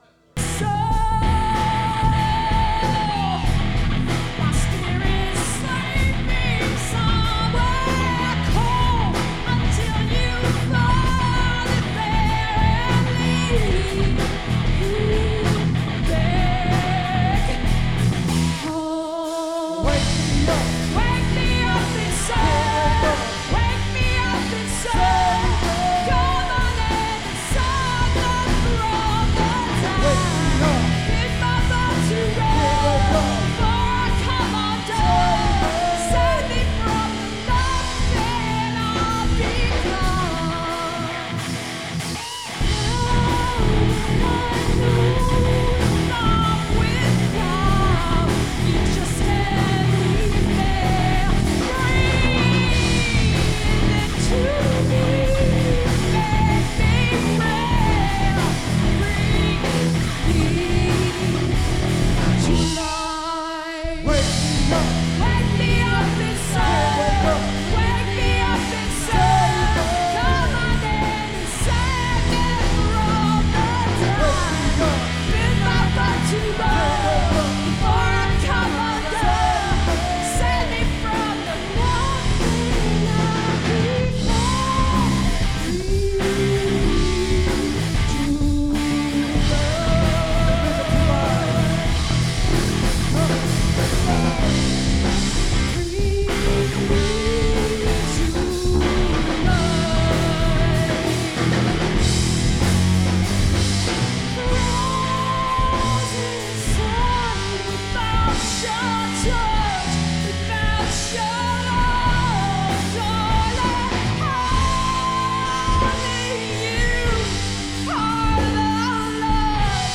party rock band